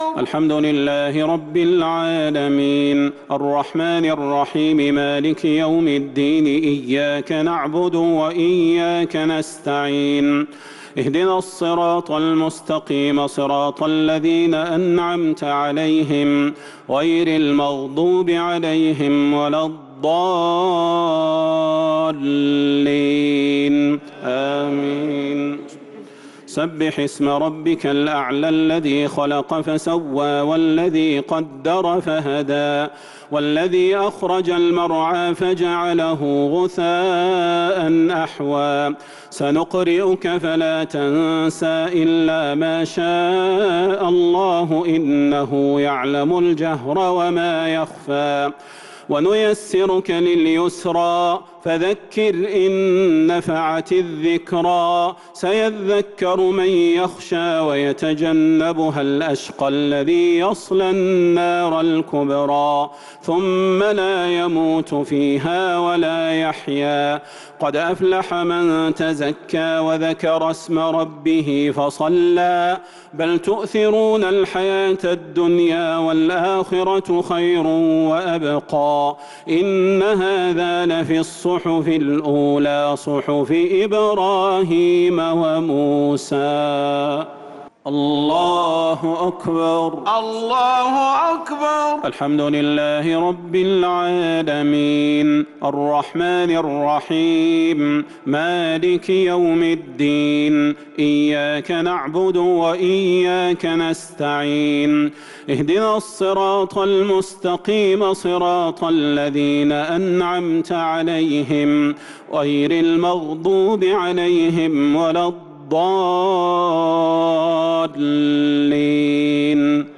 الشفع و الوتر ليلة 19 رمضان 1443هـ | Witr 19 st night Ramadan 1443H > تراويح الحرم النبوي عام 1443 🕌 > التراويح - تلاوات الحرمين